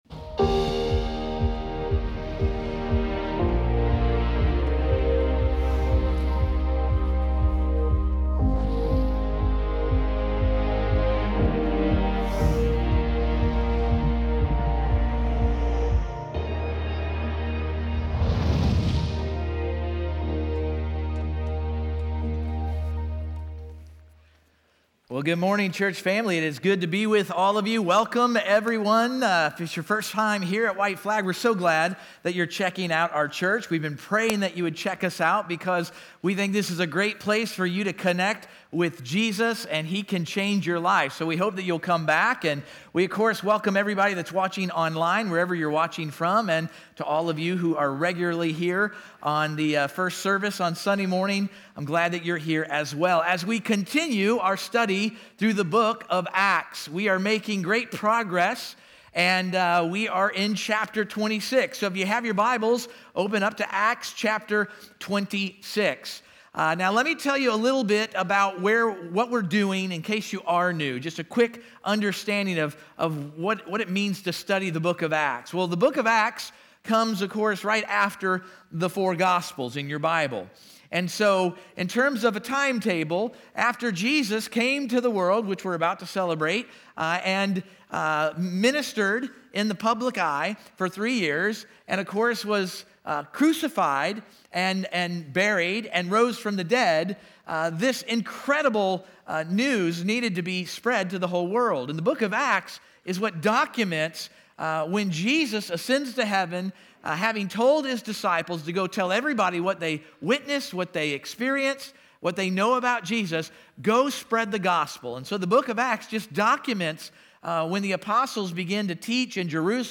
acts-26-sermon.mp3